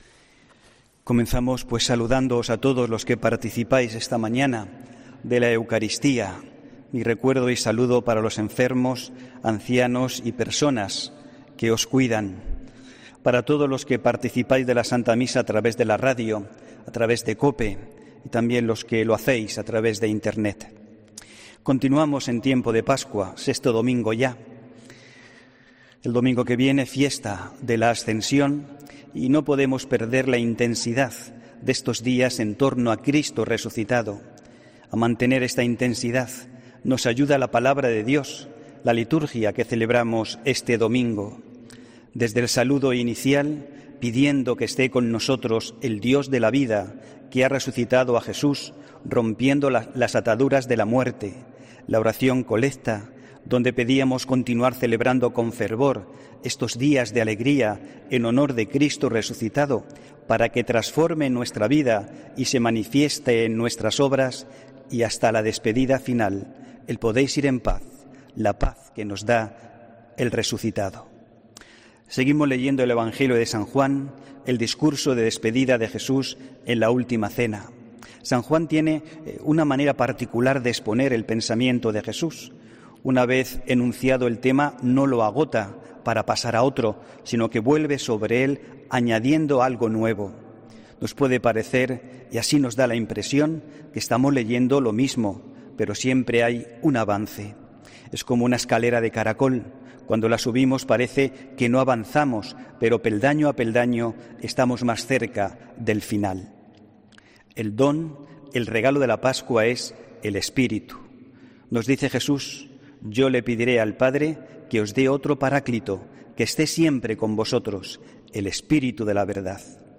HOMILÍA 17 MAYO 2020